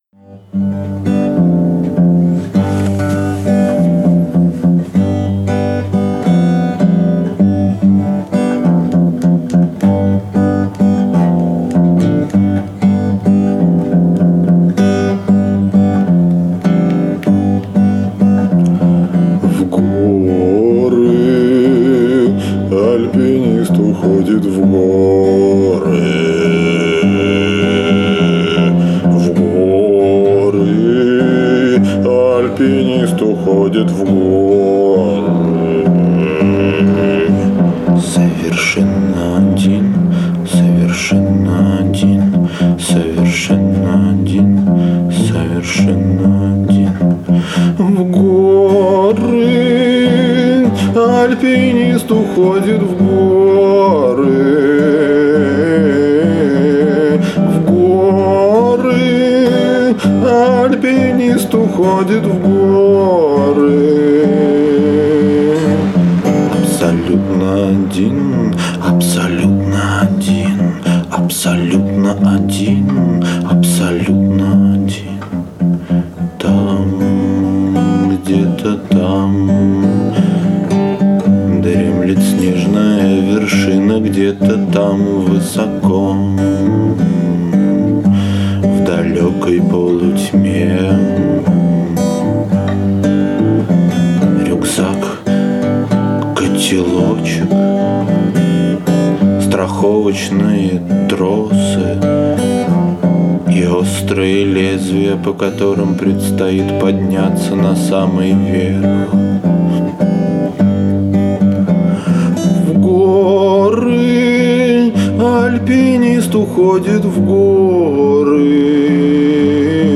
по старой доброй традиции записались вдвоем на диктофон
вокал
гитара    Обложка